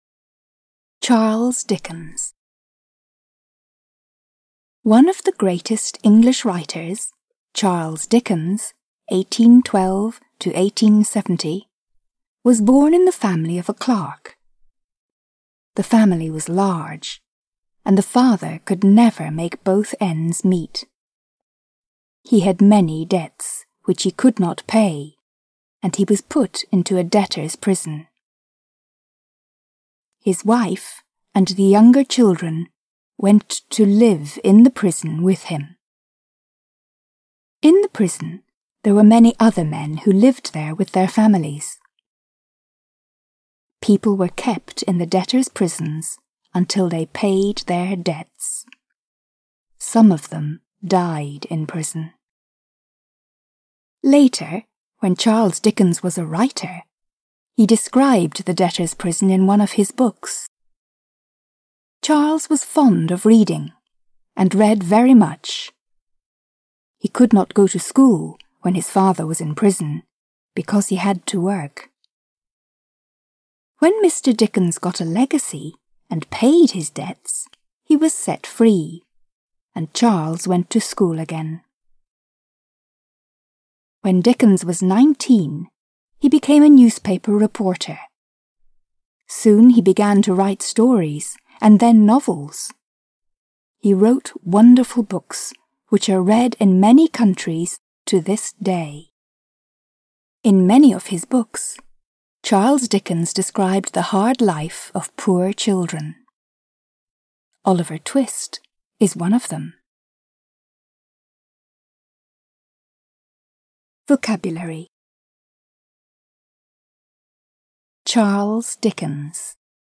Аудиокнига Oliver Twist | Библиотека аудиокниг